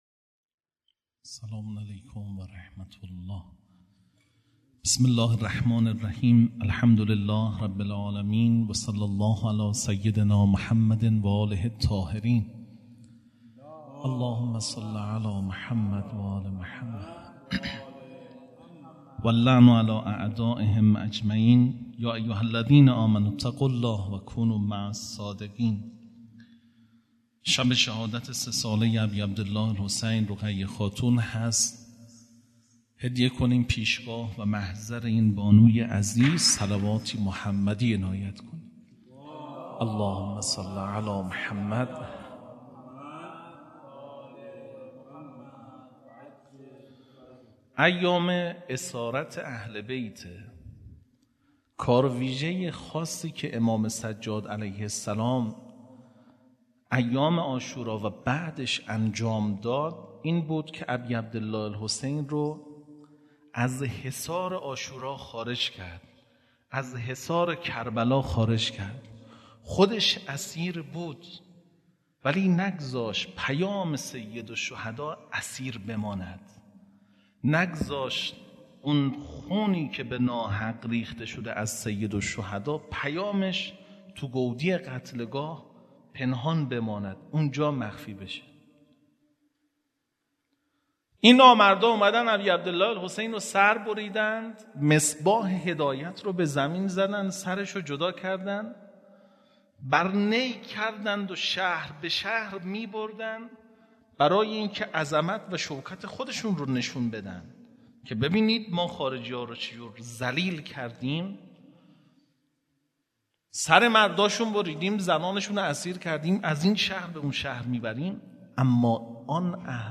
سخنرانی
هیئت دانشجویی فاطمیون دانشگاه یزد